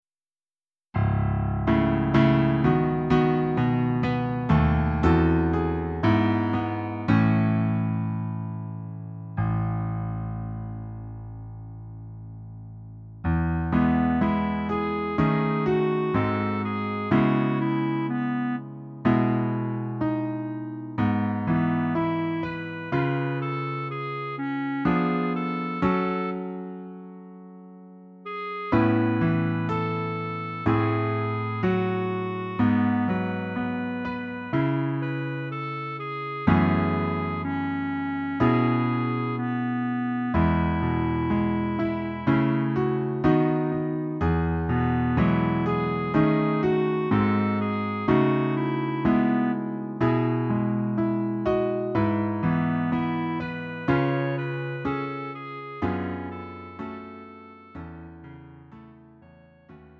음정 원키 4:11
장르 구분 Lite MR
Lite MR은 저렴한 가격에 간단한 연습이나 취미용으로 활용할 수 있는 가벼운 반주입니다.